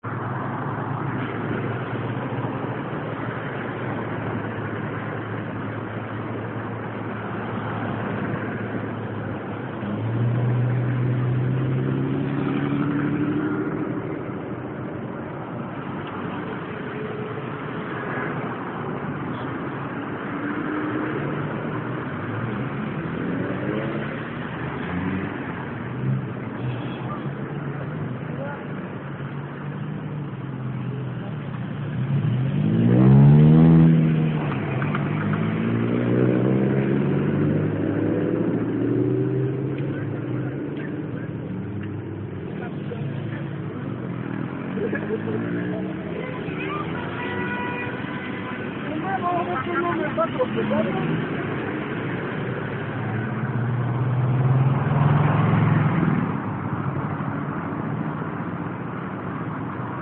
描述：墨西哥城博物馆演示人们唱歌
声道立体声